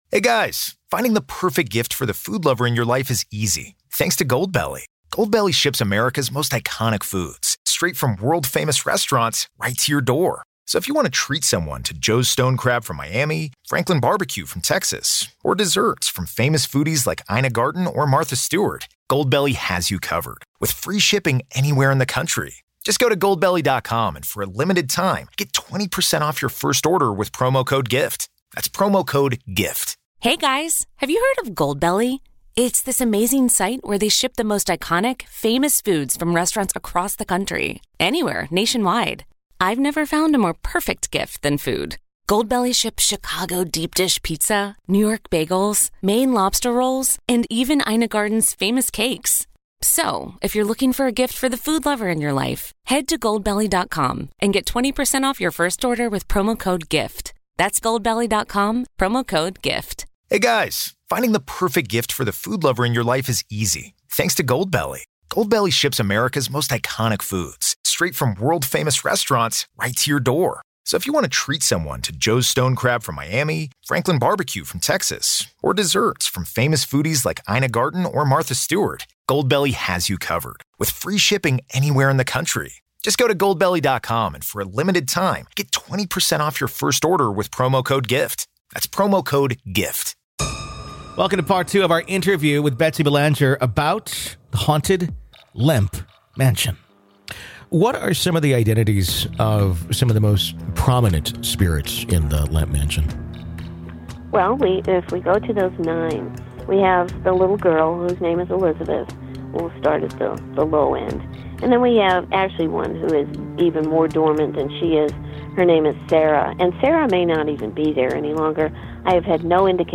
It’s a story of a family empire built on beer, broken by misfortune, and remembered by ghosts. This is Part Two of our conversation.